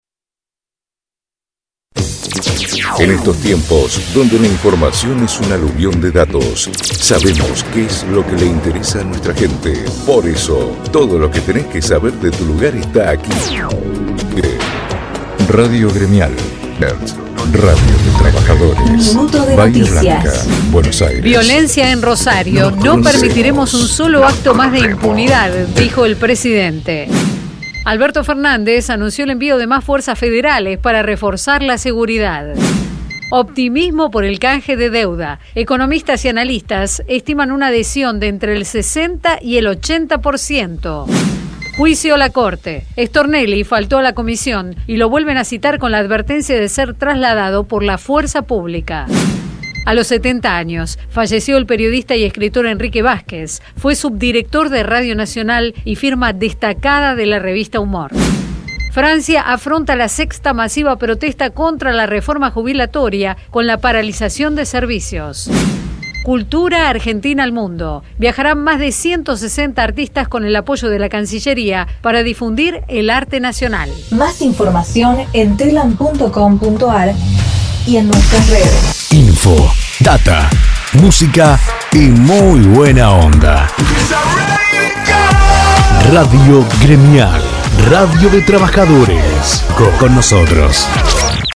NOTICIAS :RESUMEN DE LA MAÑANA CON AGENCIA TELAM - RADIO GREMIAL Bahía Blanca